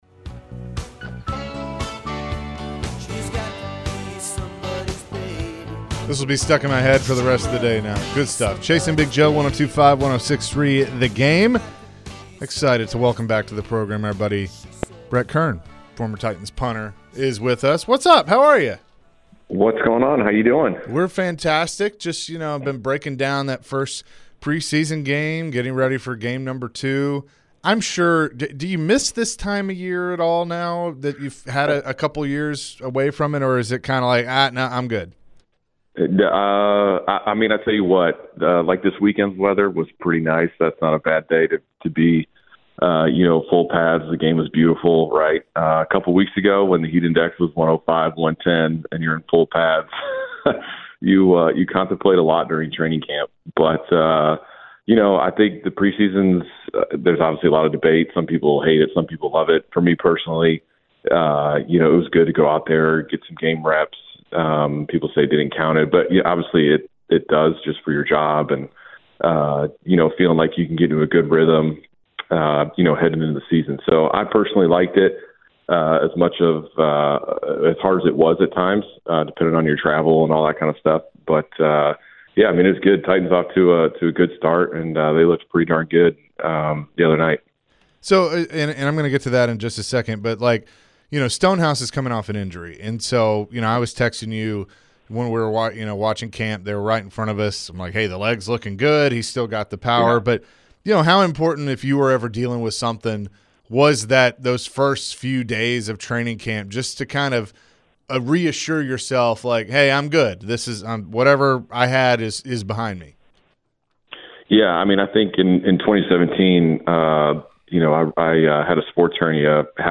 Former Titans Punter Brett Kern joined the show and shared his thoughts about the Titans roster and the new kickoff format. Is Brett a fan of the new dynamic kickoff?